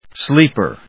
音節sléep・er 発音記号・読み方
/ˈslipɝ(米国英語), ˈsli:pɜ:(英国英語)/